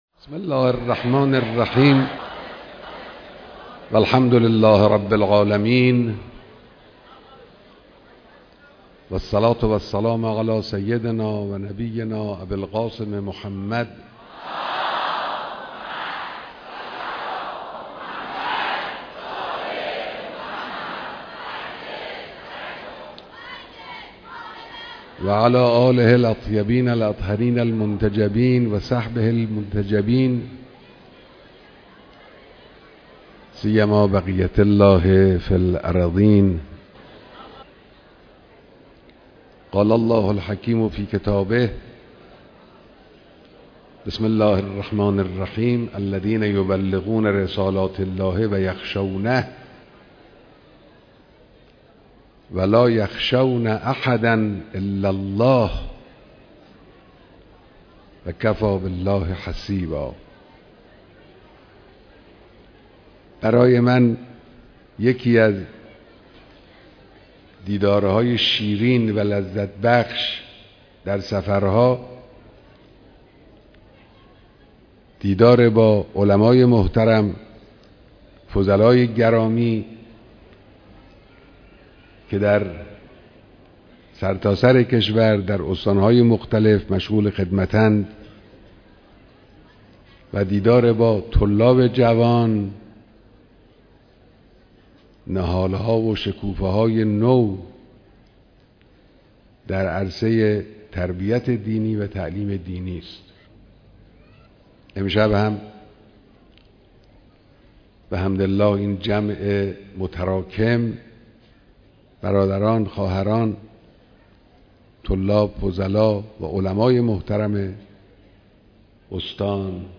بیانات در جمع علما و روحانیون شیعه و سنی